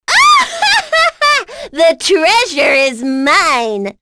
Miruru-Vox_Victory.wav